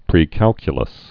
(prē-kălkyə-ləs)